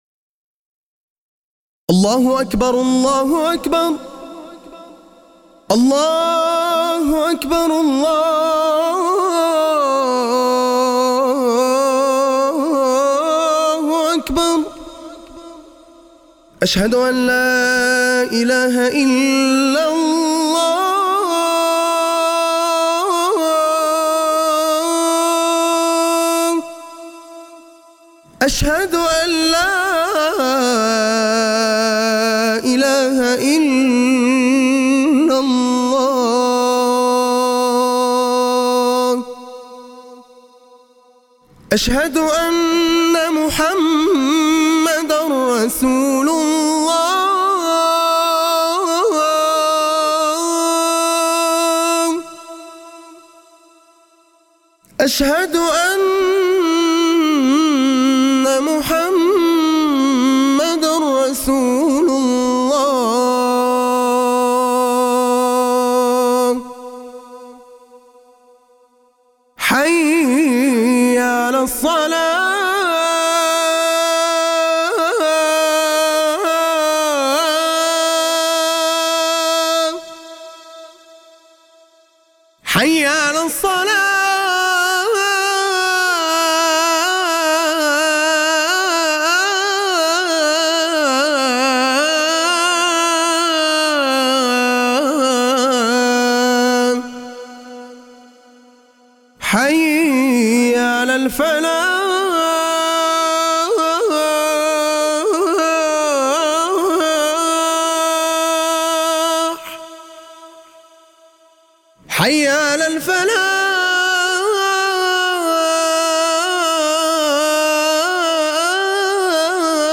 الاذان.mp3